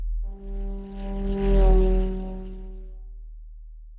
pgs/Assets/Audio/Sci-Fi Sounds/Movement/Fly By 05_5.wav at 7452e70b8c5ad2f7daae623e1a952eb18c9caab4
Fly By 05_5.wav